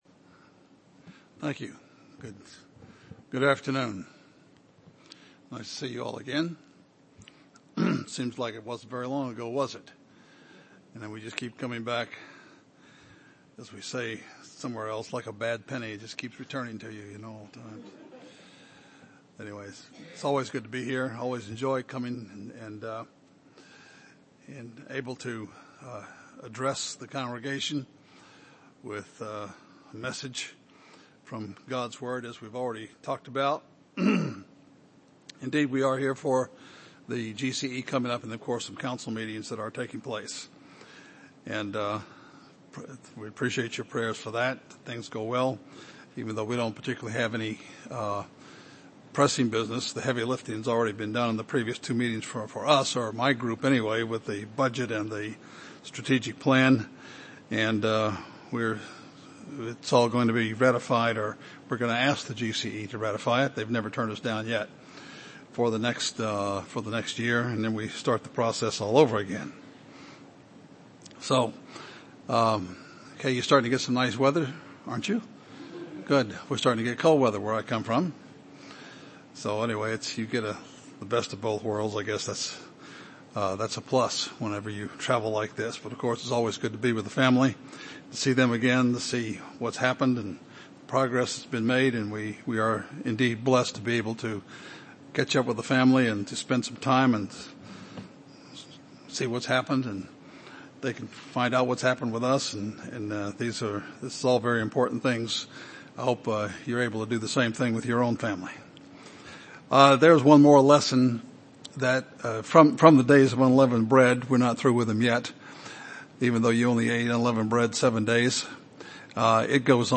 UCG Sermon journey change Studying the bible?